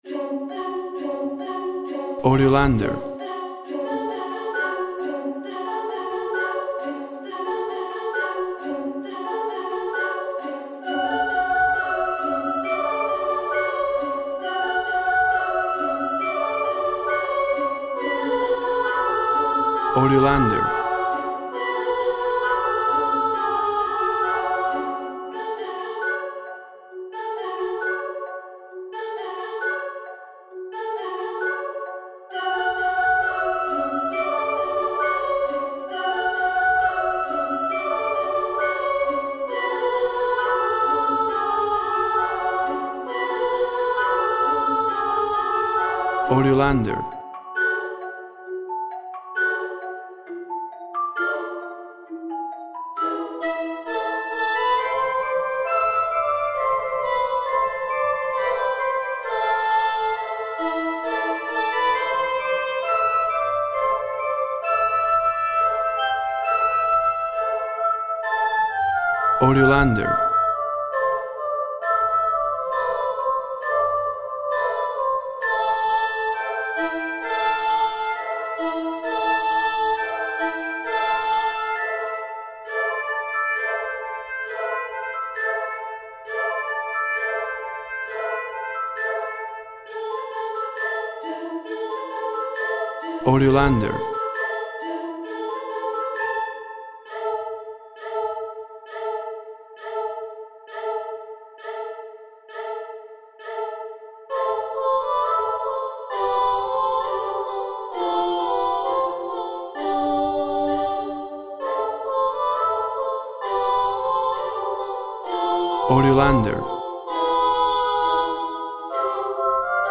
Boys choir sings lively but dark/mysterious song.
Tempo (BPM) 128